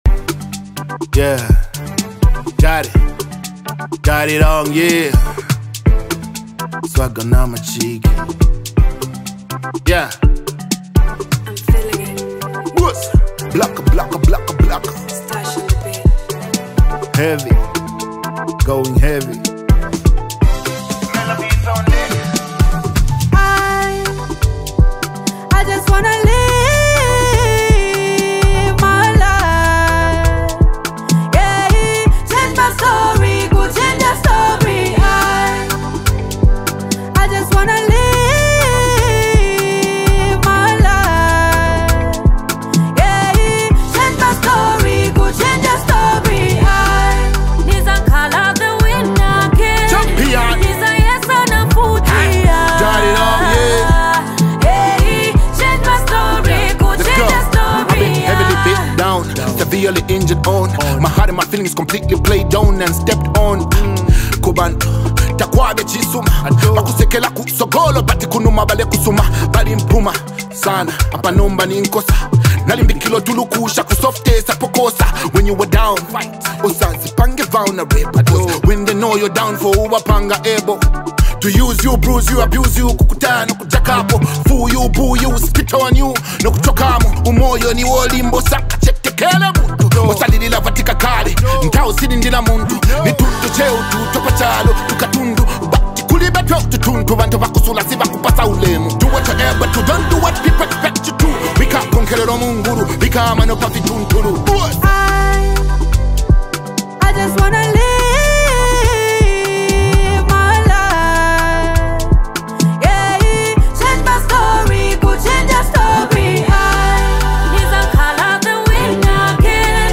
soulful duo
lays down heartfelt verses full of real-life reflections